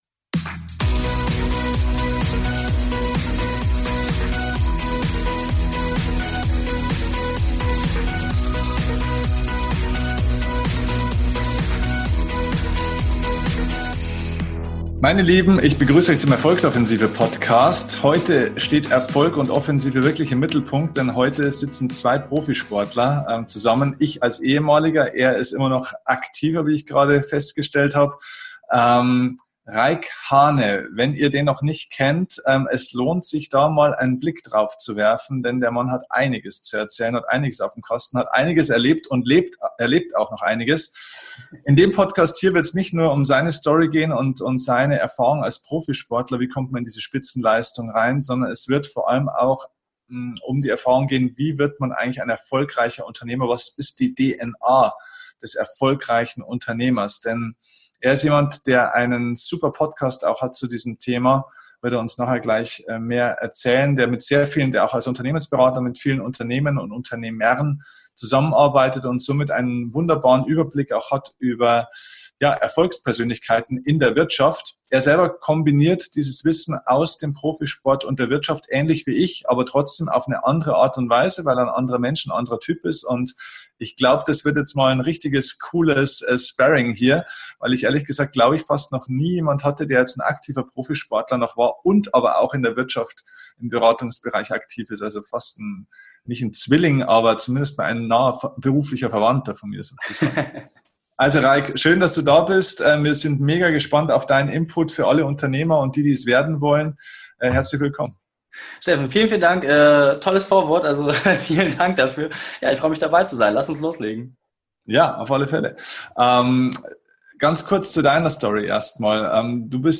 Heute spricht er im Interview über die wichtigen Faktoren, um ein erfolgreiches Business aufzubauen. https